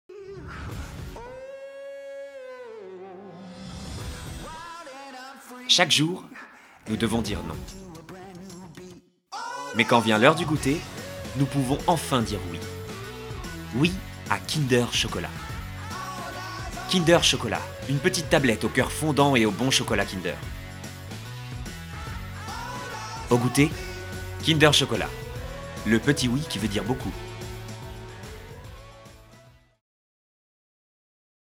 Voix off
Narration - Calme, posé